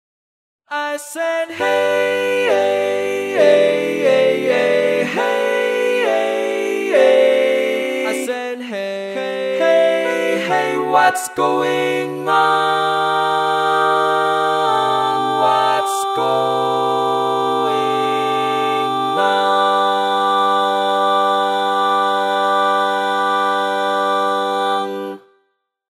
Key written in: G Major
How many parts: 4
Type: Barbershop
All Parts mix:
Learning tracks sung by